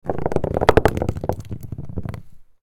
Rolling Metal Ball Sound Effect
A heavy metal ball rolling on a concrete surface. Game sounds.
Rolling-metal-ball-sound-effect.mp3